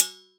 Petit échantillonnage d’objets collectés dans ma cuisine, enregistrés à l’aide d’une mailloche.
sample-casserole-b-1_96khz_24b_mono.wav